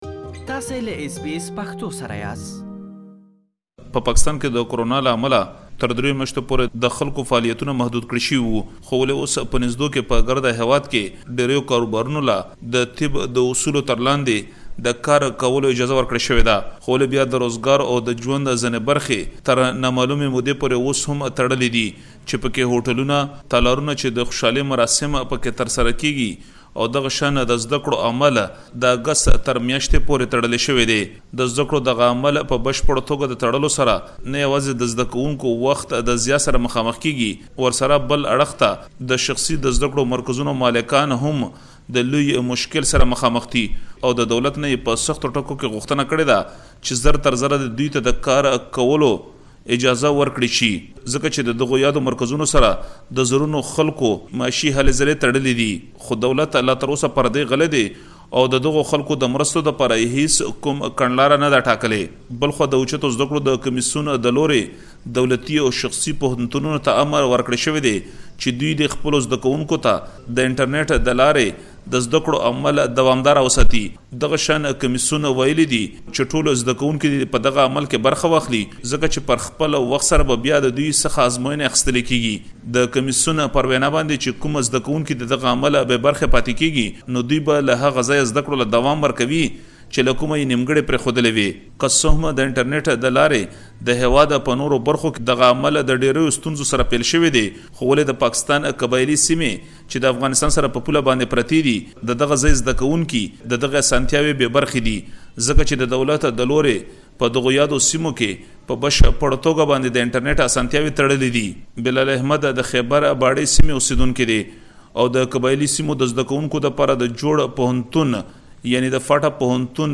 له قبايلي زده کوونکو سره خبرې کړي او د هغوی غږونه يې راخيستي